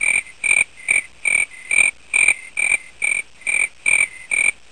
grillen.wav